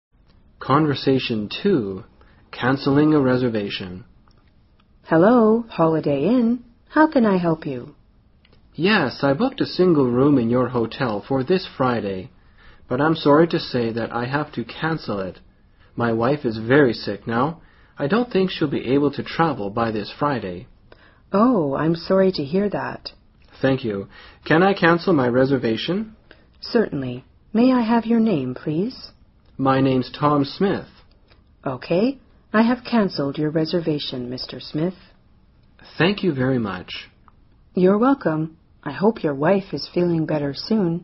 【对话2：打电话取消预定的宾馆】